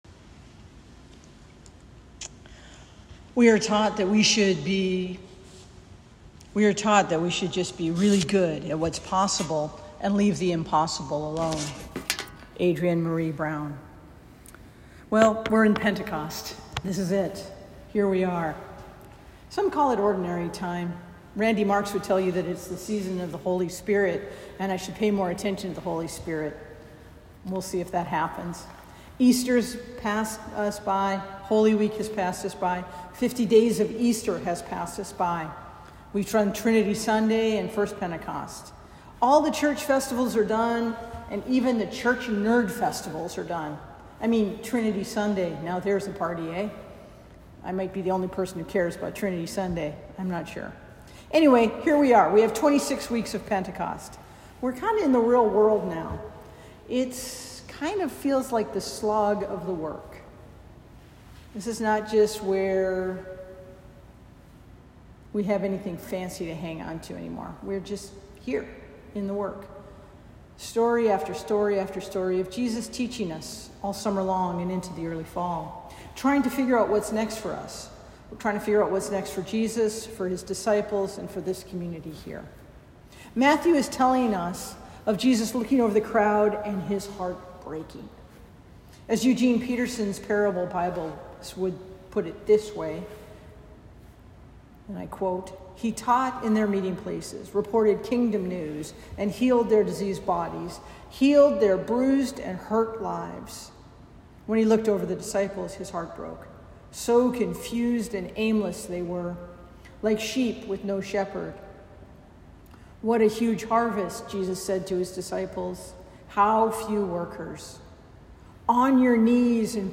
All Lives Do Not Matter Unless Black Lives Matter : St. Mark’s Episcopal Church | Capitol Hill, Washington, DC